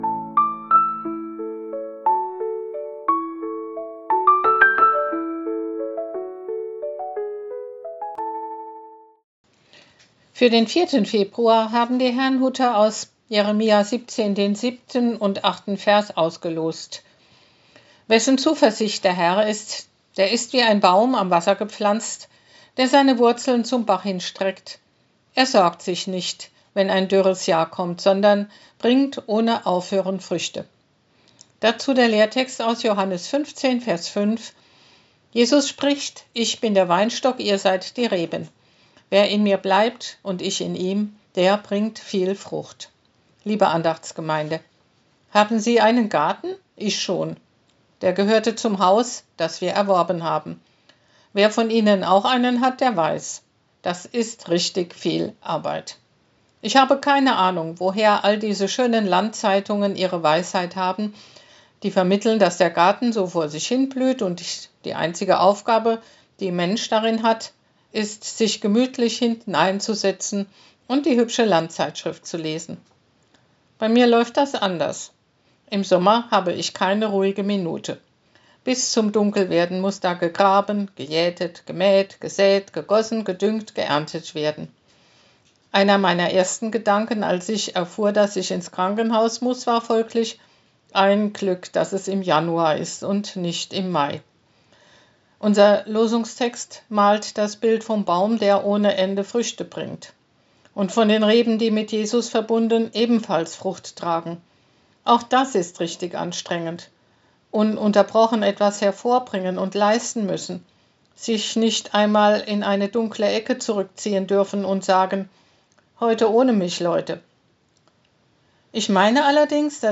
Losungsandacht für Mittwoch, 04.02.2026 – Prot.